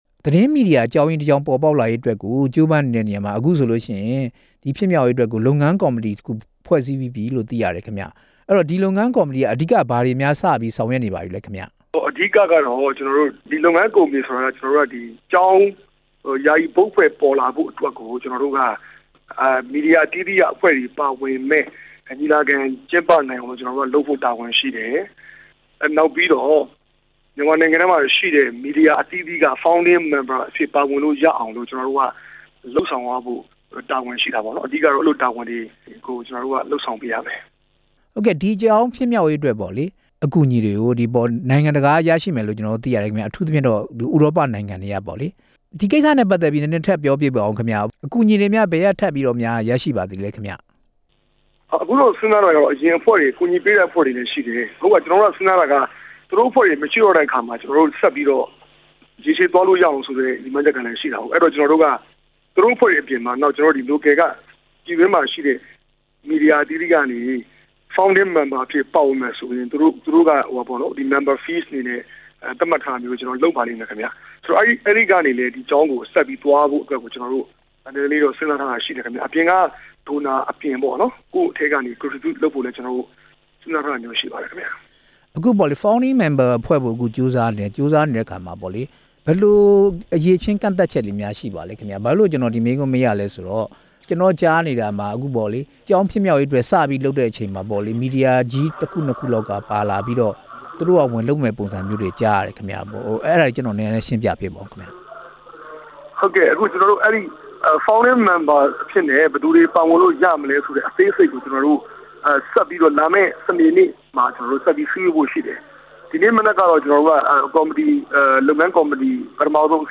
ဆက်သွယ် မေးမြန်းချက်